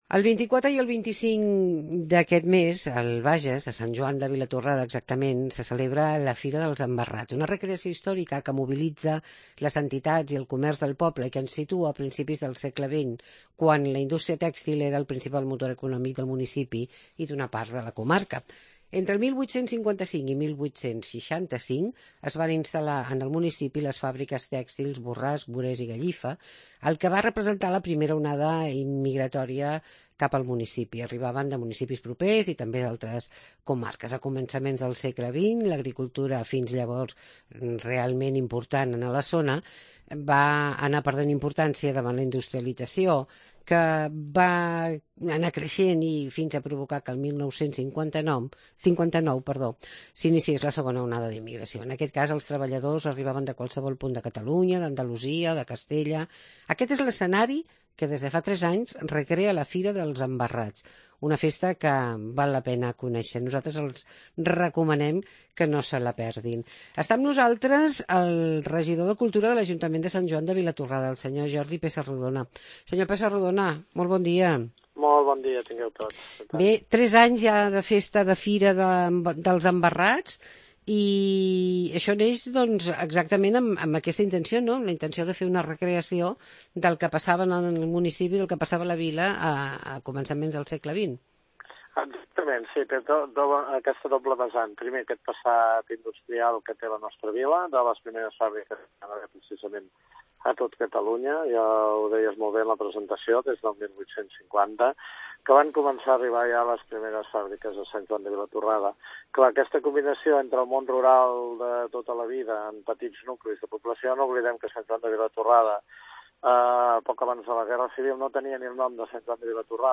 Embarrats a Sant Joan de Vilatorrada. Entrevista amb Jordi Pesarrodona, regidor de cultura